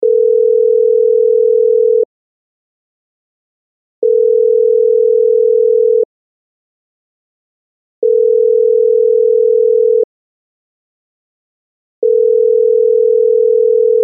Cell Phone Ringing 4 Times